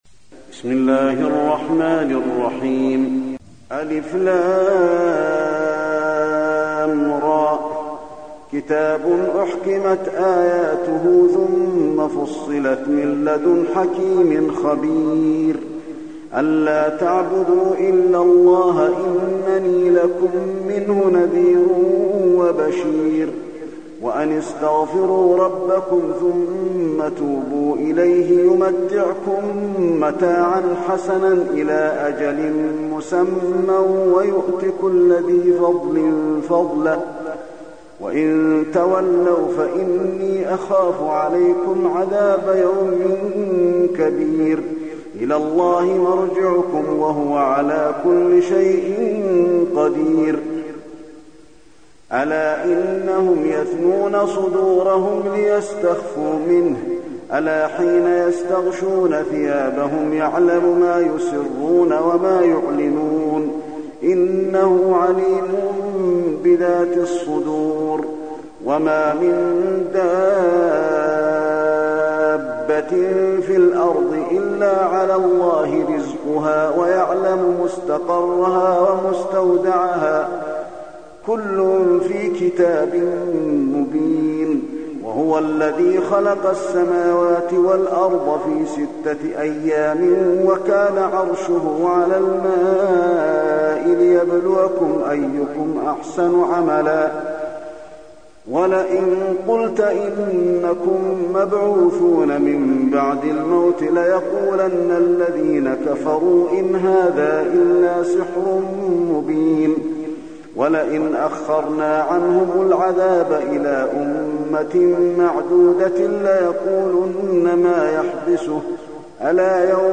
المكان: المسجد النبوي هود